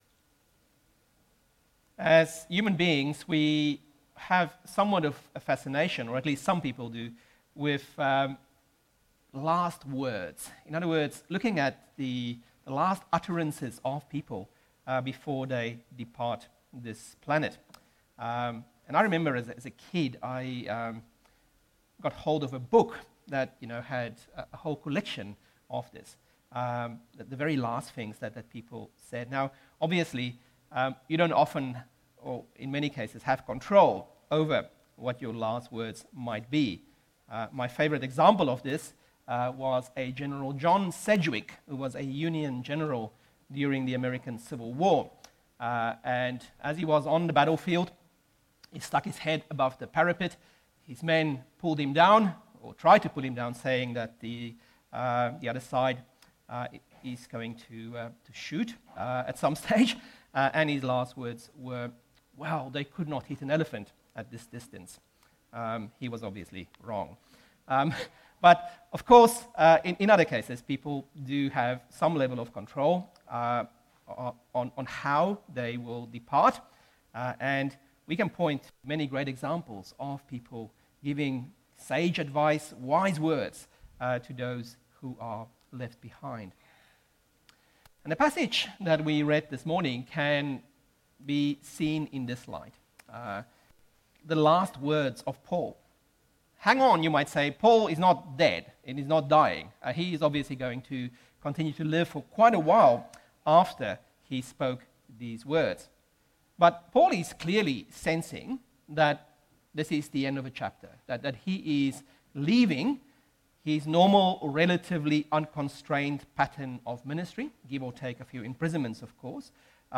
Passage: Acts 20: 17-38 Service Type: AM